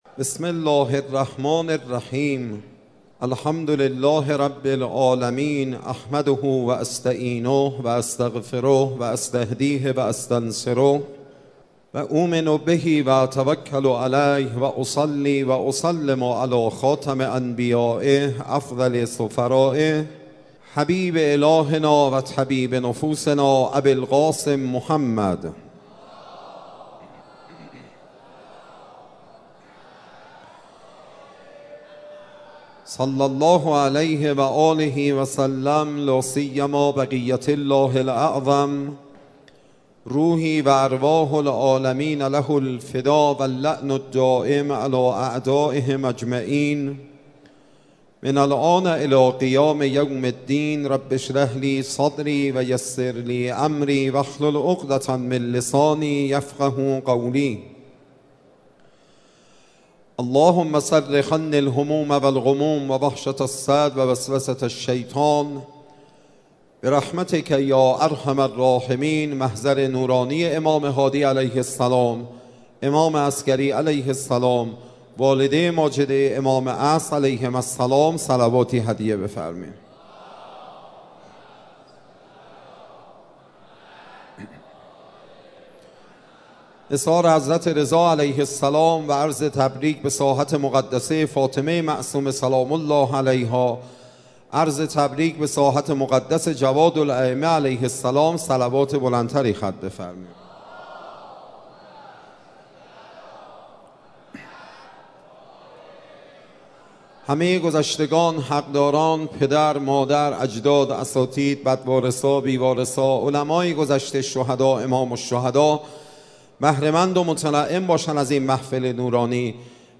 تکیه | محفل معارفی حرم مطهر